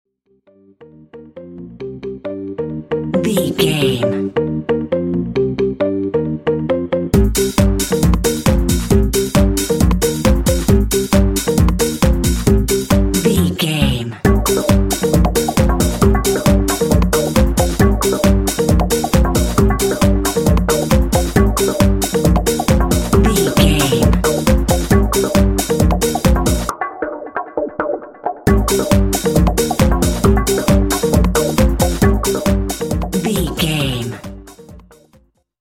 Aeolian/Minor
dreamy
futuristic
groovy
lively
energetic
drums
synthesiser
drum machine
house
techno
trance
electro
euro house
synth lead
synth bass